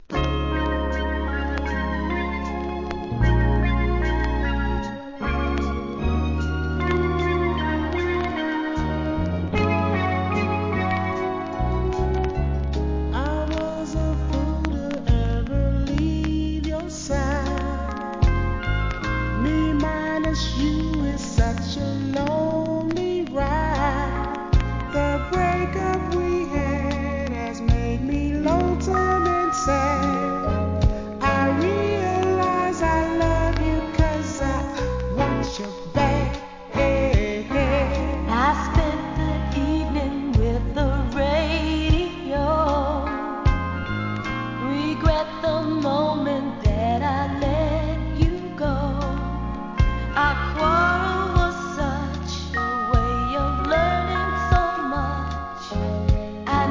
1. SOUL/FUNK/etc...
1978年、NICEデュエット♪ B/Wも息ピッタリの好DISCO!! US